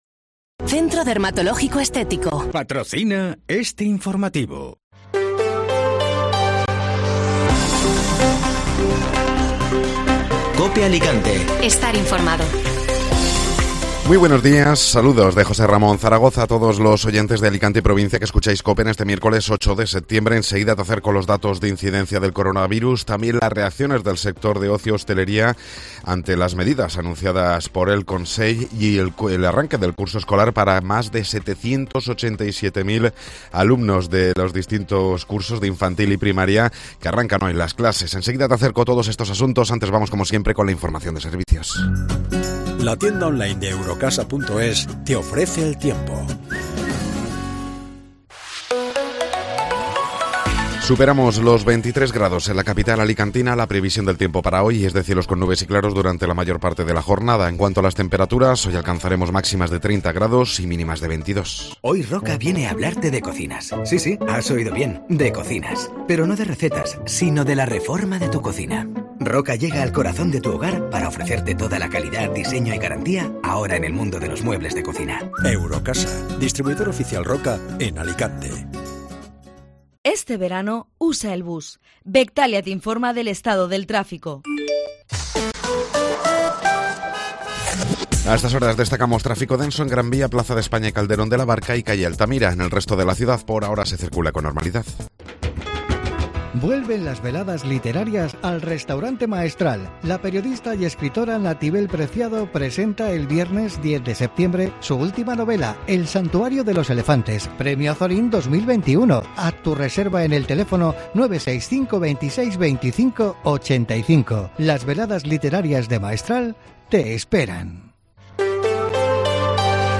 Informativo Matinal (Miércoles 8 de Septiembre)